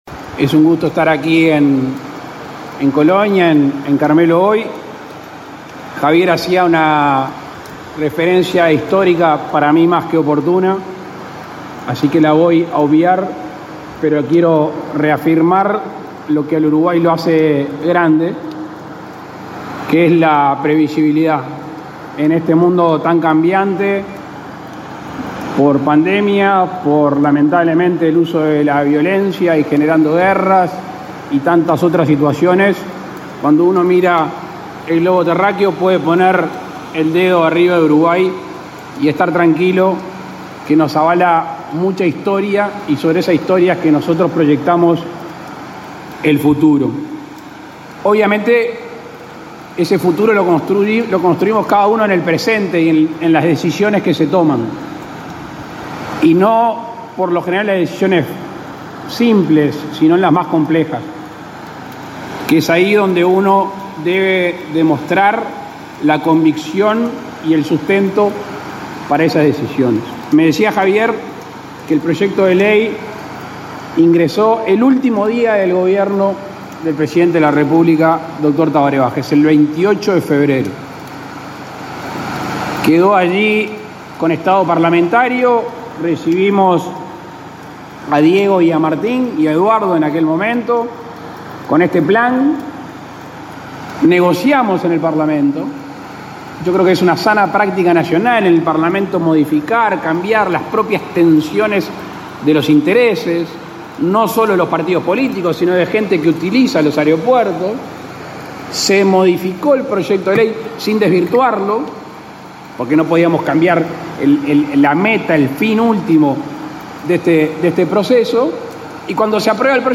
Palabras del presidente Luis Lacalle Pou
Palabras del presidente Luis Lacalle Pou 09/03/2022 Compartir Facebook X Copiar enlace WhatsApp LinkedIn El presidente Luis Lacalle Pou encabezó este martes 9 el acto de colocación de la piedra fundamental del nuevo aeropuerto internacional de Carmelo, en el departamento de Colonia.